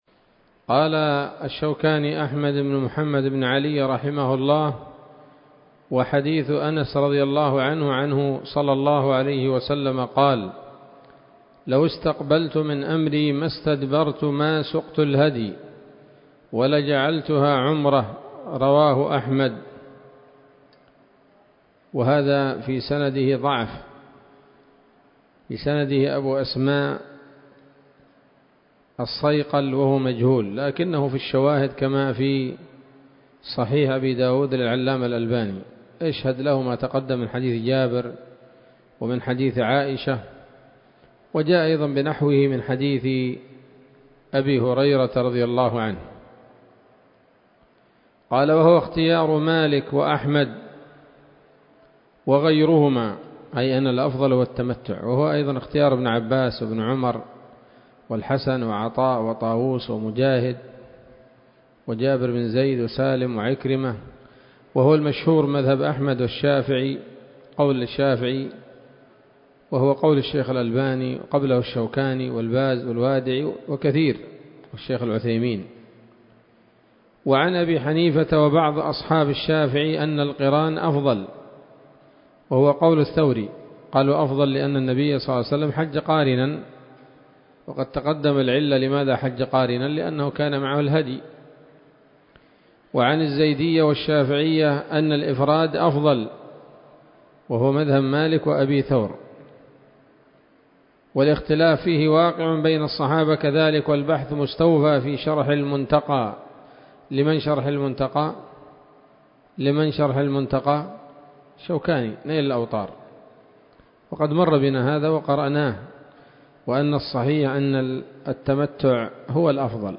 الدرس الخامس من كتاب الحج من السموط الذهبية الحاوية للدرر البهية